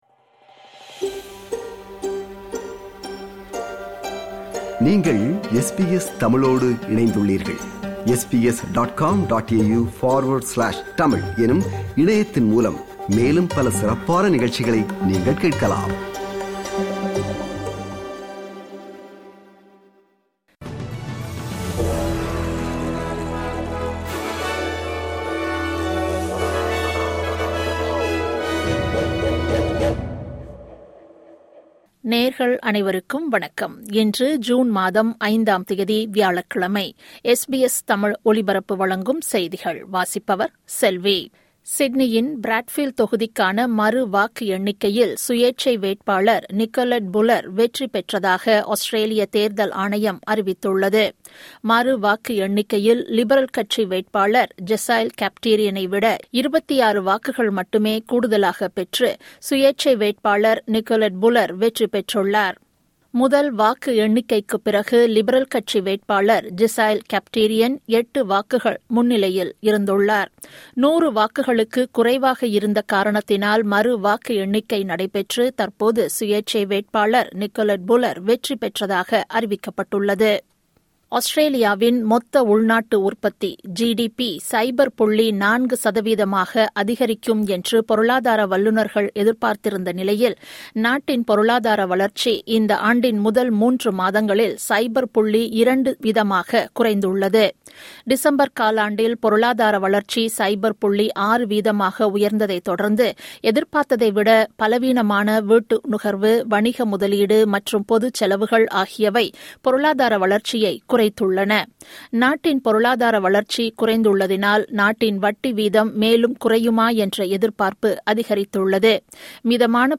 SBS தமிழ் ஒலிபரப்பின் இன்றைய (வியாழக்கிழமை 05/06/2025) செய்திகள்.